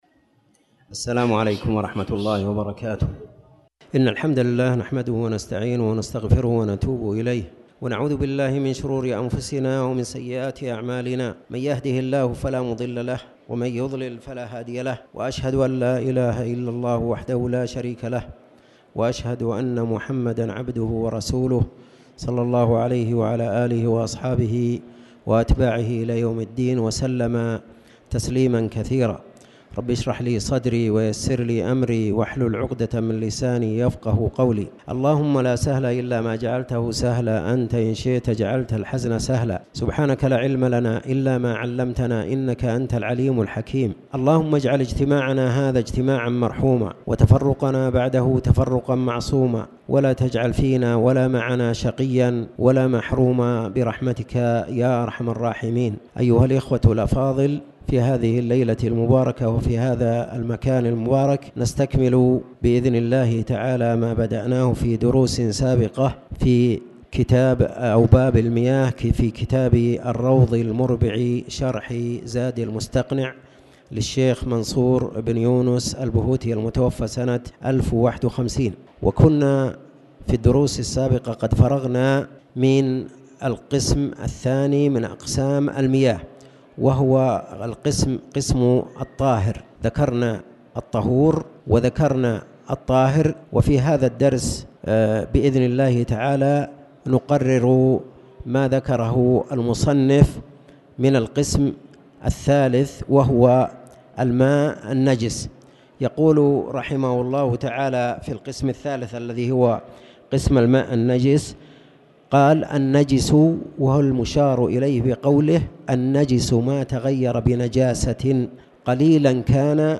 تاريخ النشر ٢١ ربيع الثاني ١٤٣٩ هـ المكان: المسجد الحرام الشيخ